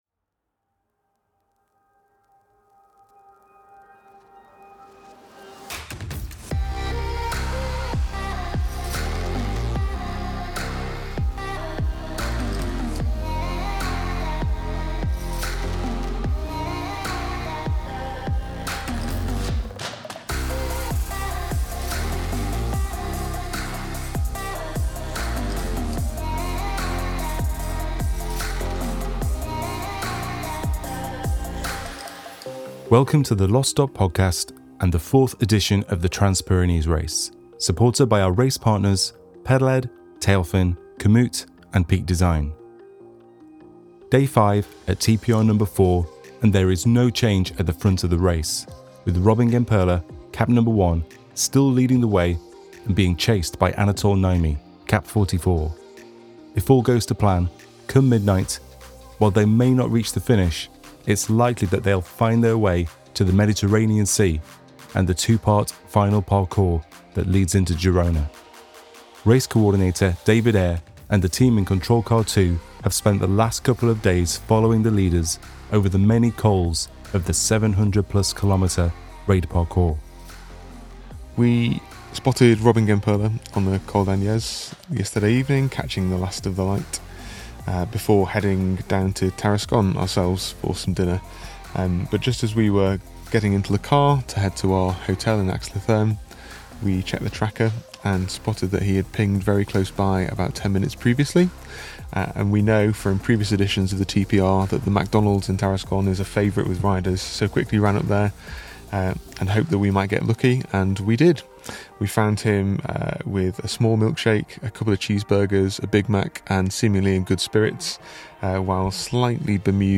Hear the sounds of the chase as they ride into their last night on the Race.